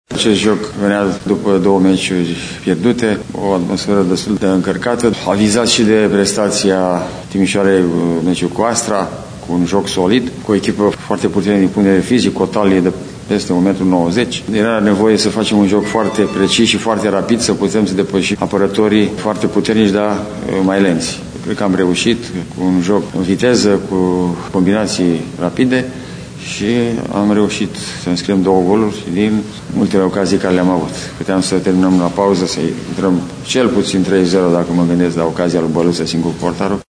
Gigi-Multescu-antrenor-CSU-Craiova.mp3